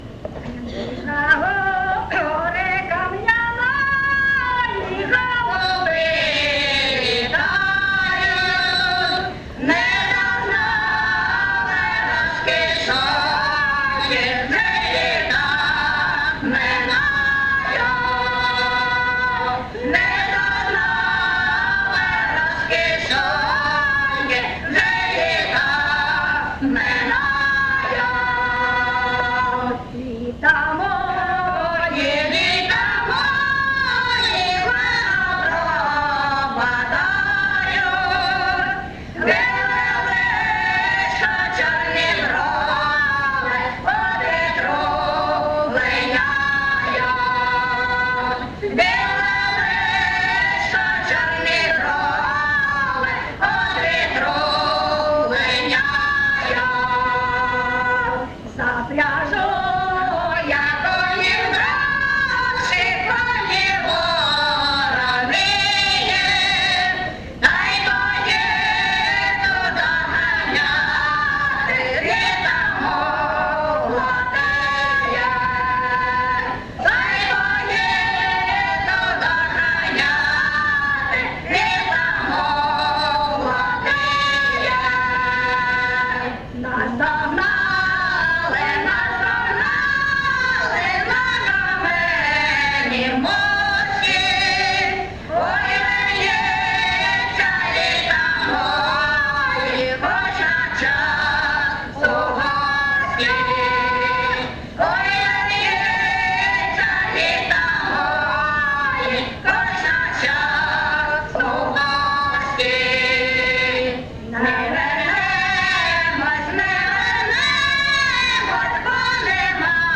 ЖанрПісні з особистого та родинного життя
Місце записус. Очеретове, Валківський район, Харківська обл., Україна, Слобожанщина